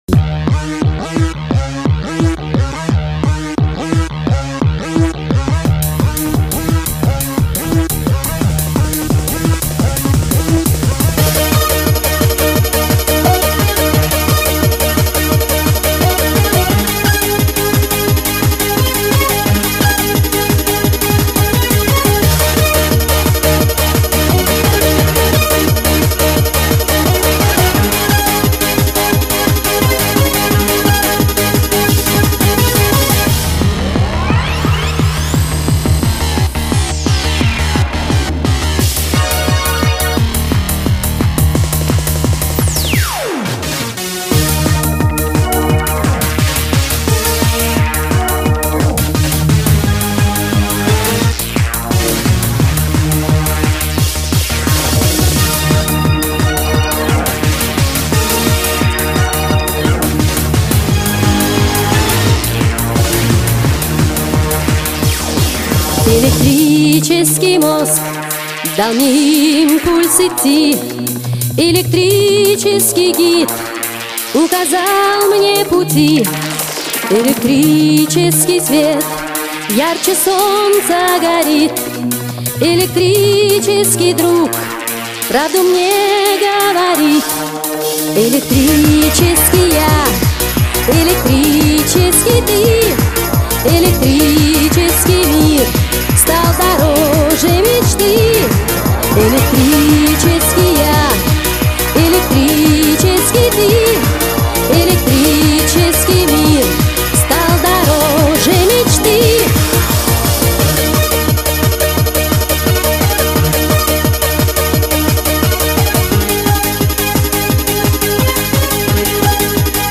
музыка 80х переделанная под клуб